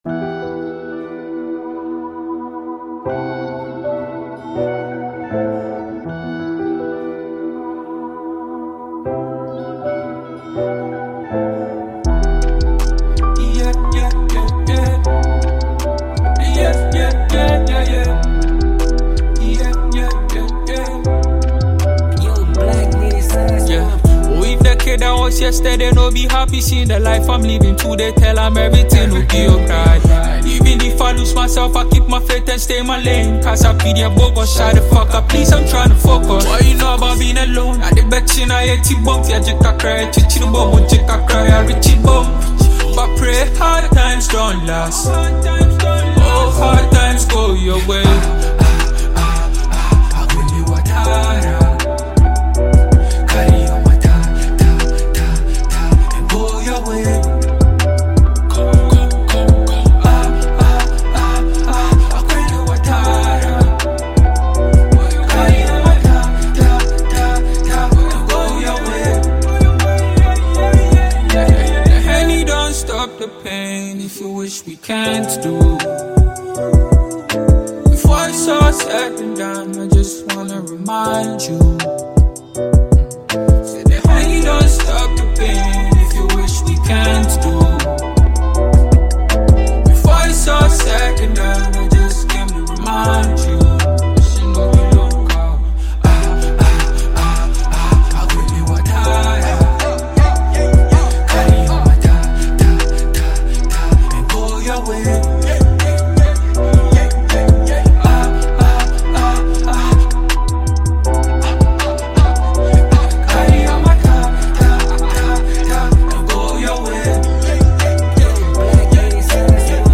Ghana